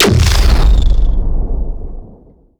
HeavyLaserLauncher1.wav